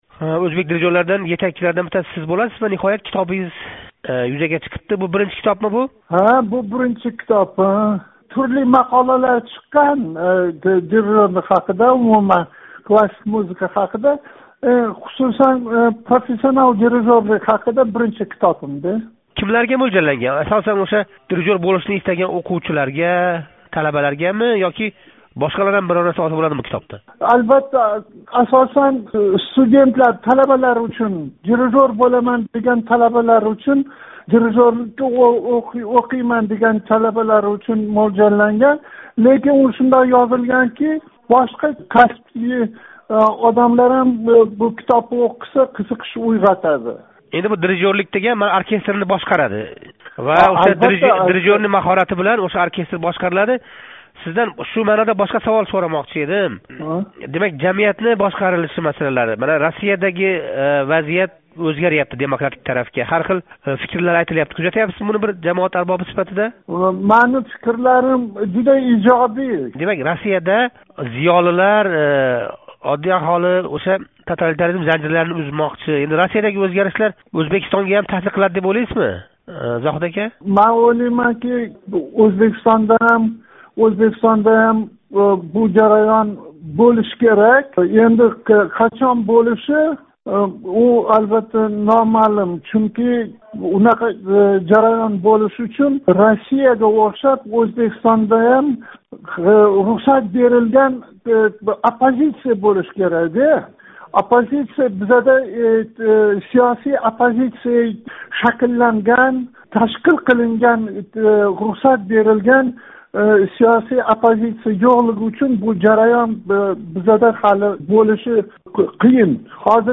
Зоҳид Ҳақназаров билан суҳбат